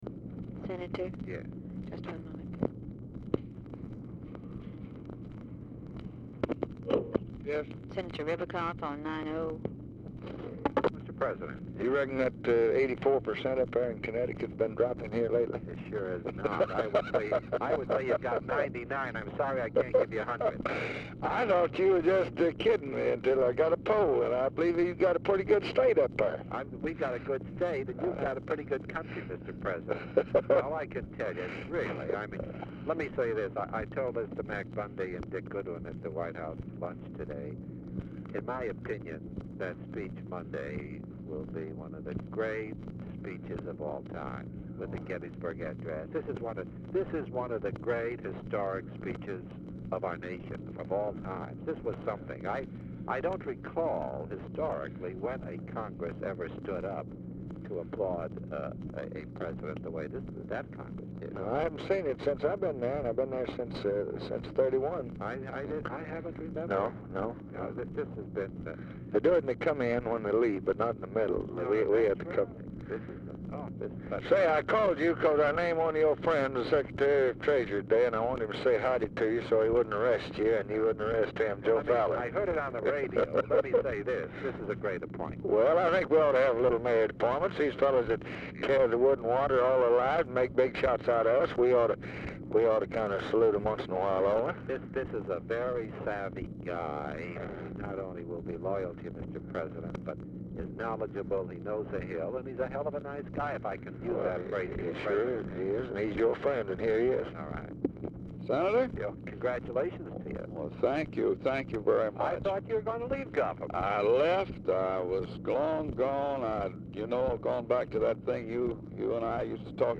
Telephone conversation # 7116, sound recording, LBJ and ABRAHAM RIBICOFF, 3/18/1965, 6:25PM | Discover LBJ